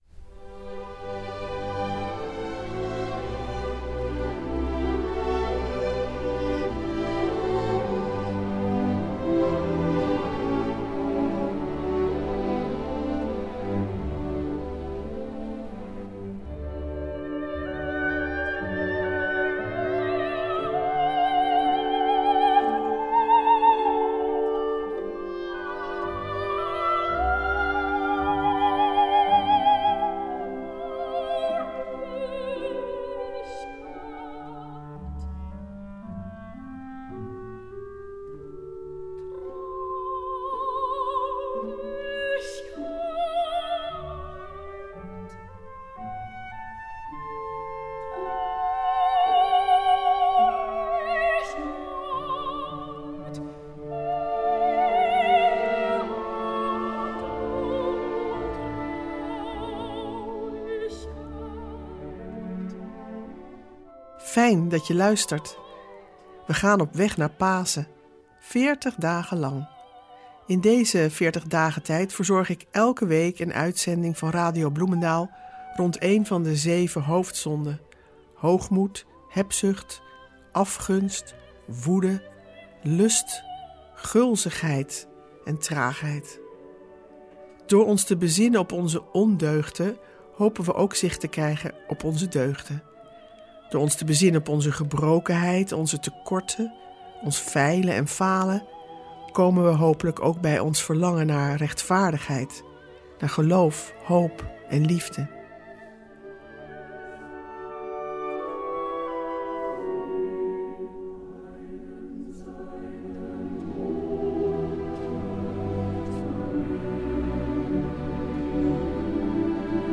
Meditaties in de Veertigdagentijd